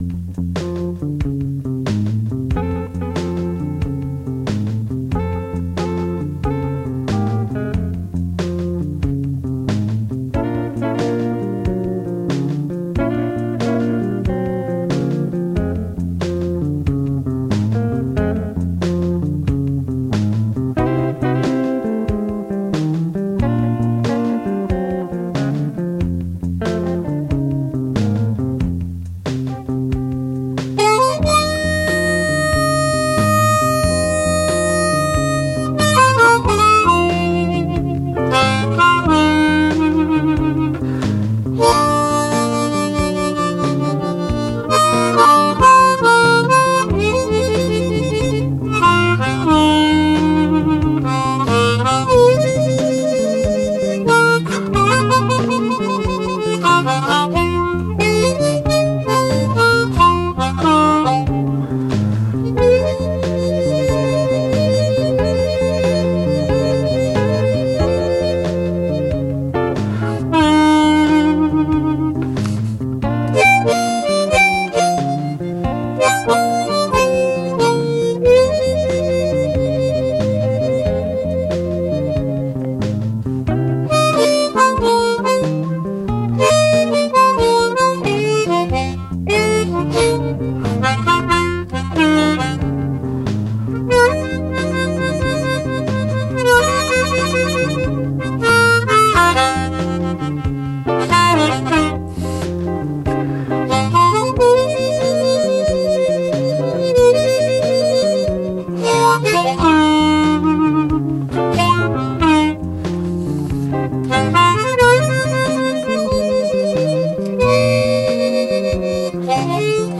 Sad Hours - Acoustic harp | Blues Harmonica